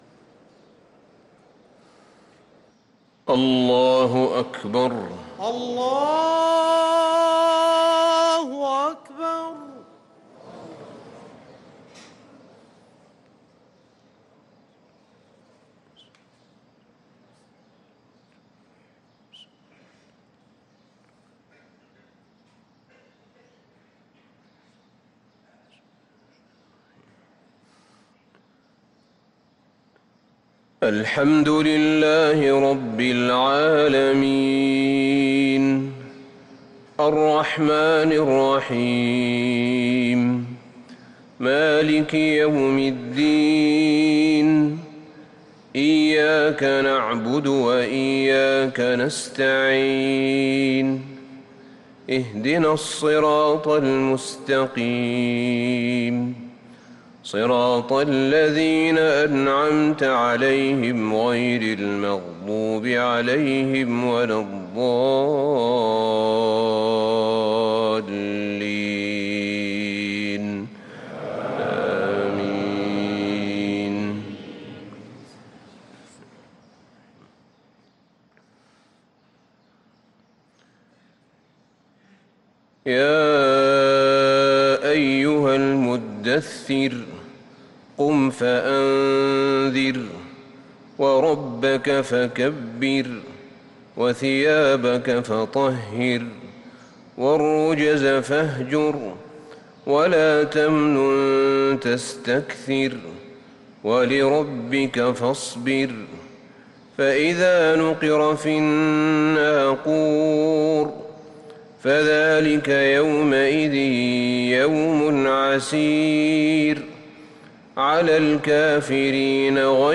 صلاة الفجر للقارئ أحمد بن طالب حميد 14 ذو القعدة 1444 هـ
تِلَاوَات الْحَرَمَيْن .